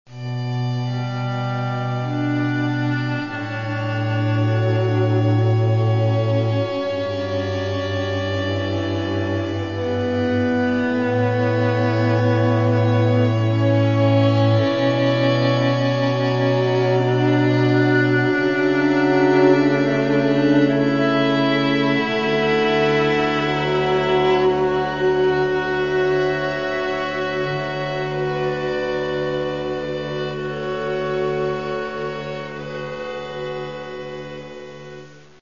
Каталог -> Классическая -> Нео, модерн, авангард